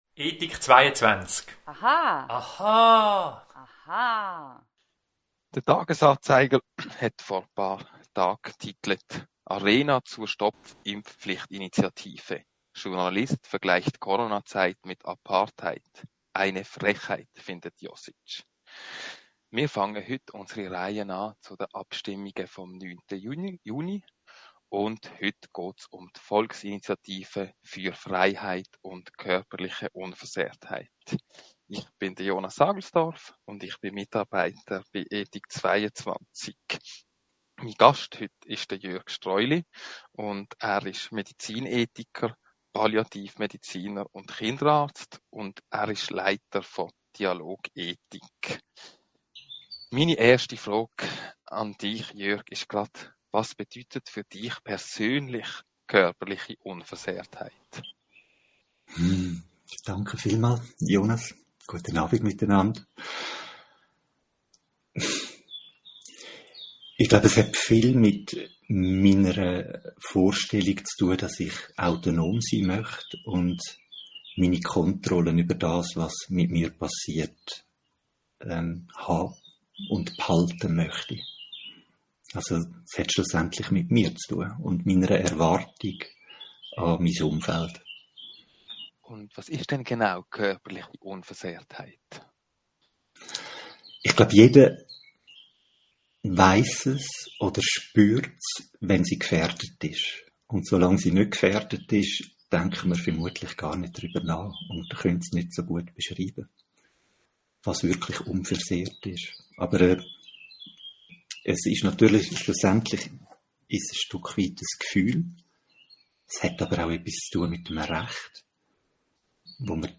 Radio🎙einFluss Audio-Gespräche informiert!